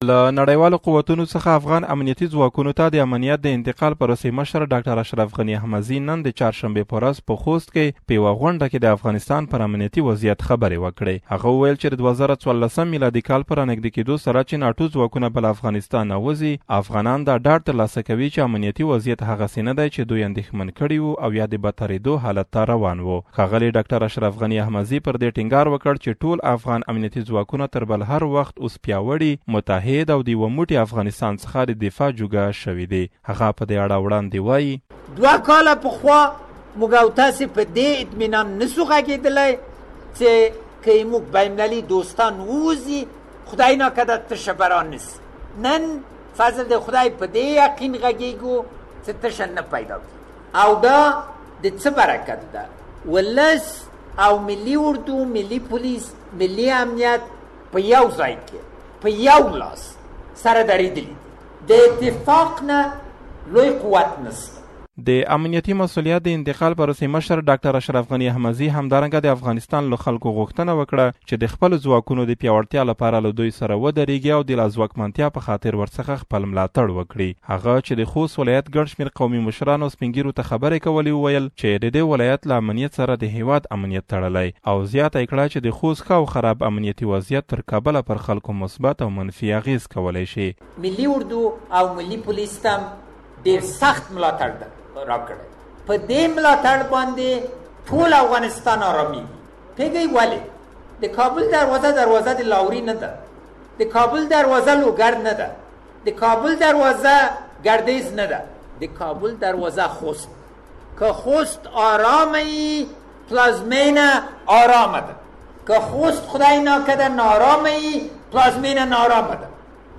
راپور دلته واوری